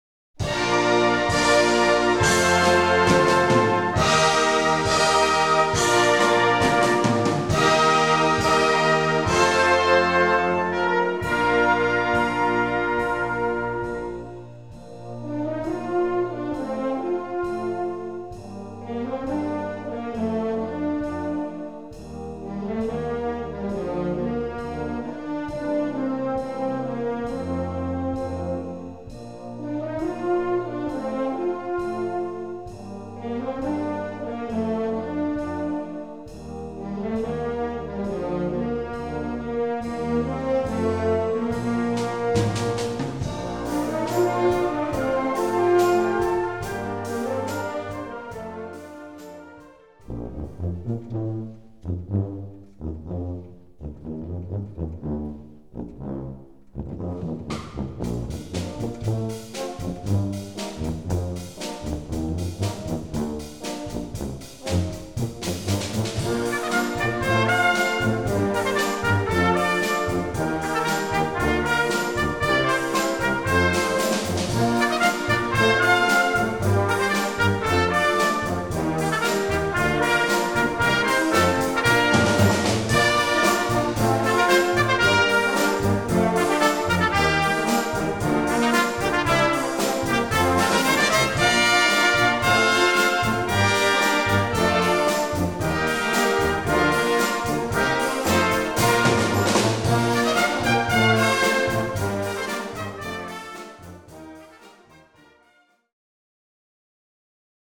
Gattung: Moderner Einzeltitel
Besetzung: Blasorchester
Moderne Melodie mit klassischen Zügen.